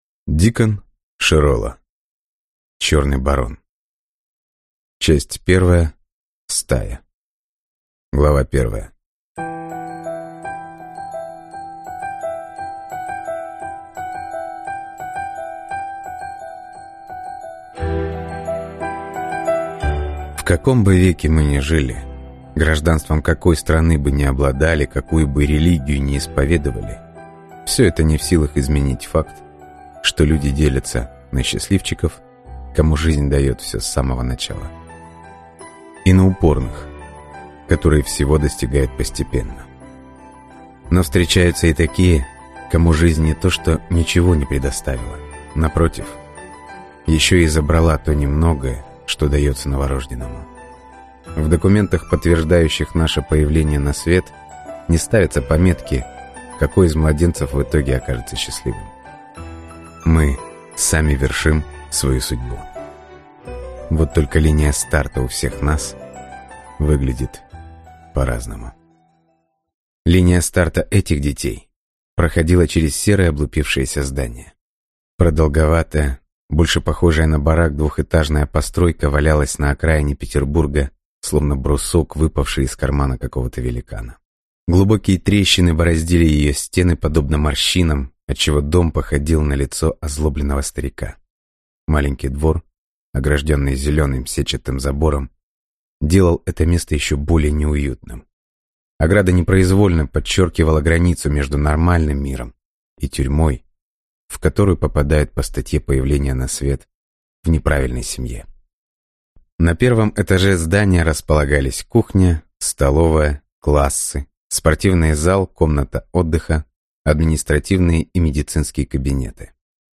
Аудиокнига Стая | Библиотека аудиокниг